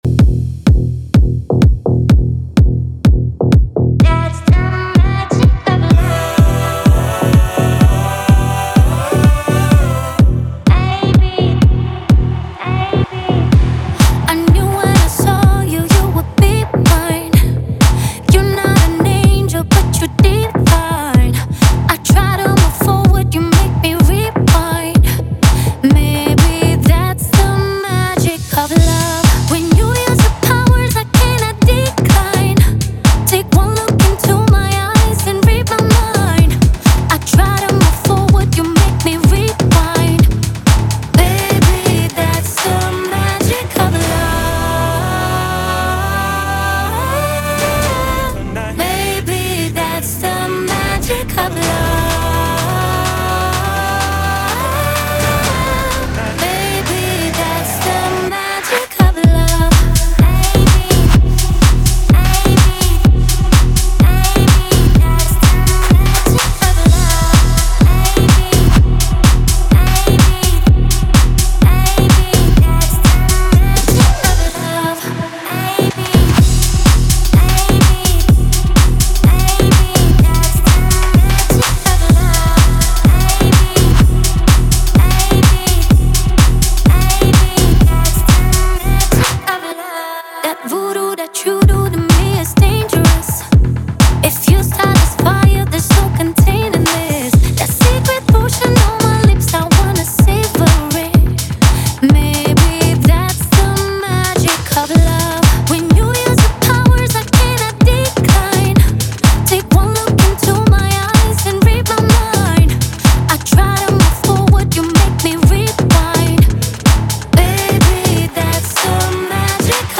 Ремиксы